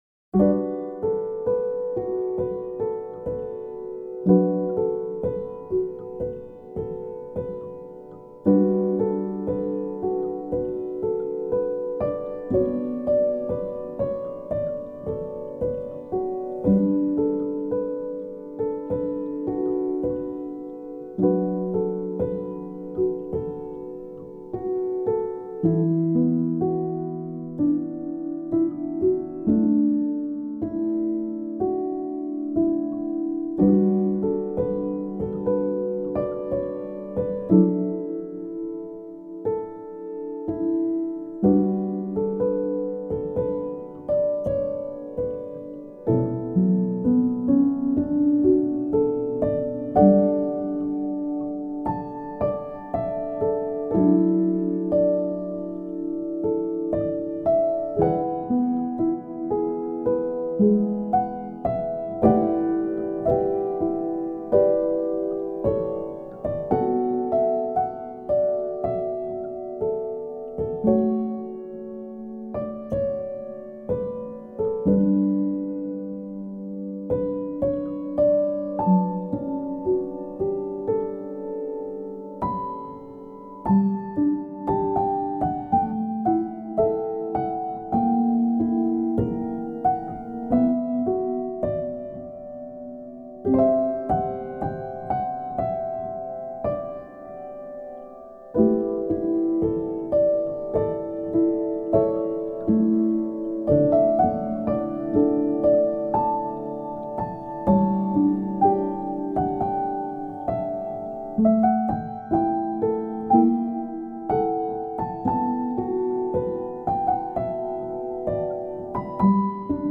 ピアノ 寝落ち 穏やか